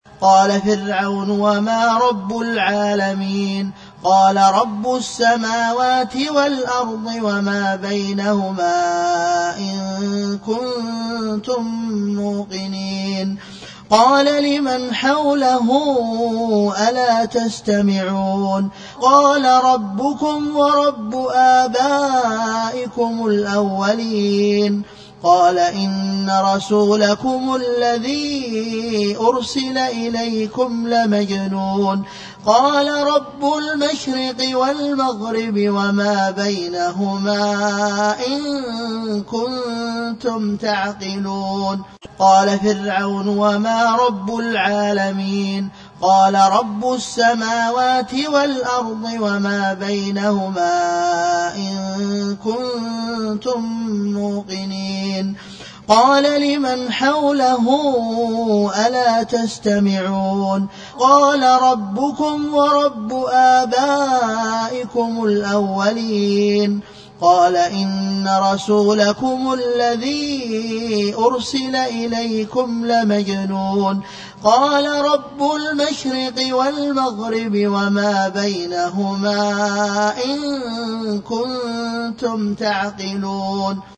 تلاوات